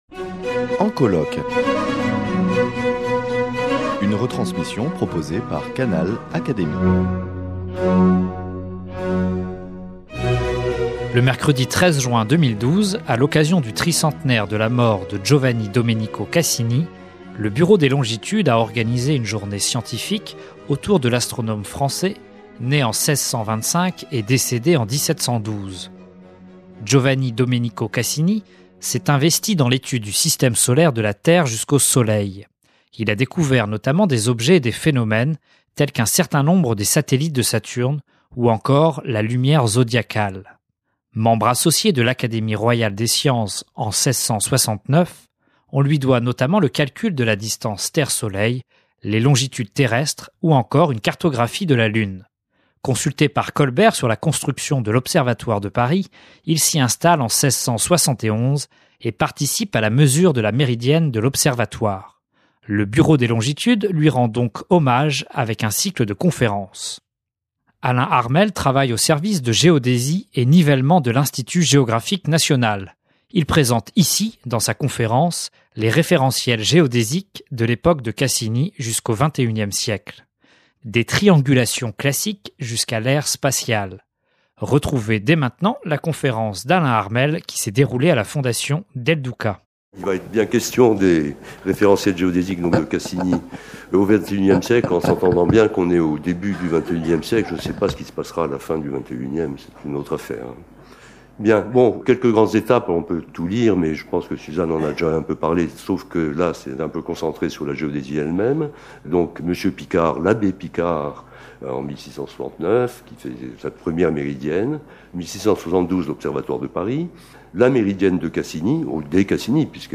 Le mercredi 13 juin 2012, à l’occasion du tricentenaire de la mort de Giovanni-Domenico Cassini, le Bureau des Longitudes a organisé une journée scientifique autour de l’astronome français né en 1625 et décédé en 1712.
Il présente ici, dans sa conférence, les référentiels géodésiques de l’époque de Cassini jusqu’ au XXI ème siècle, des triangulations classiques jusqu’à l’ère spatiale.